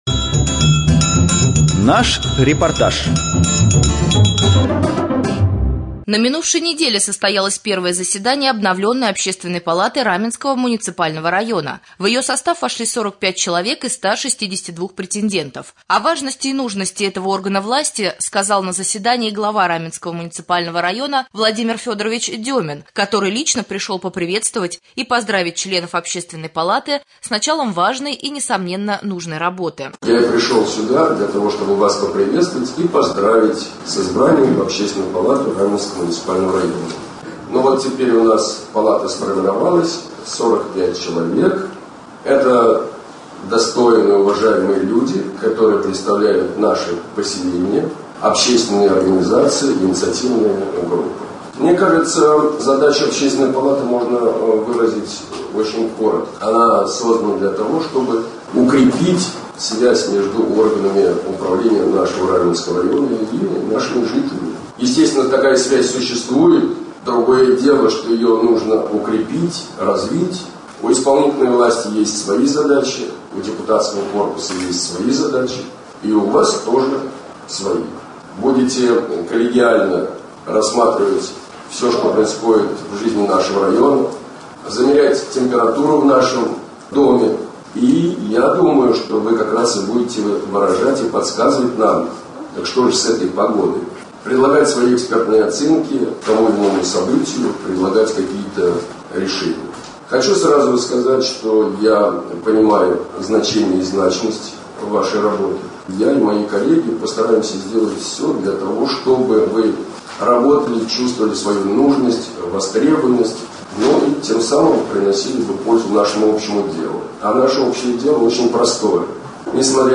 22.07.2014 в эфире Раменского радио - РамМедиа - Раменский муниципальный округ - Раменское